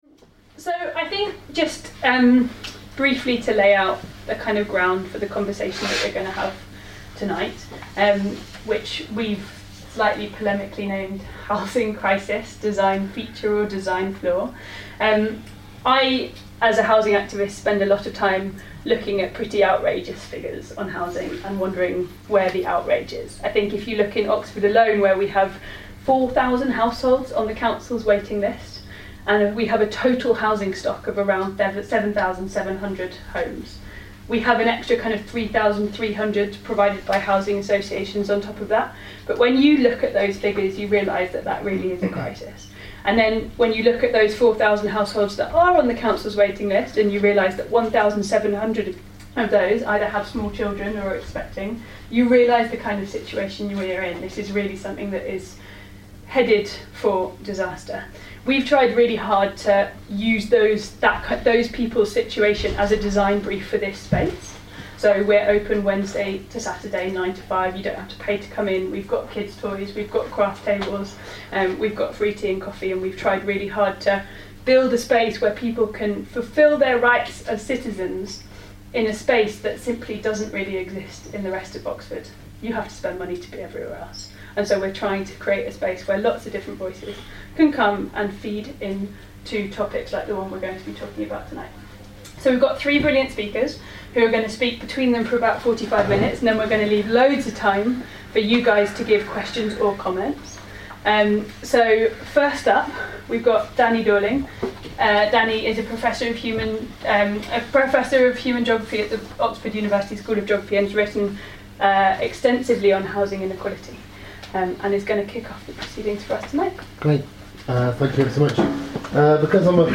An Open House discussion - 7:30pm Tuesday 27th November 2018, 36 Little Clarendon Street, Oxford.